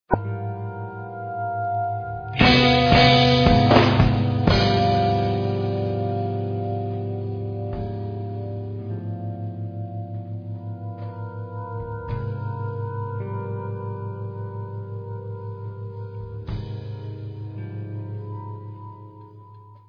30 minutes of stoner rock -minus the greasy hair-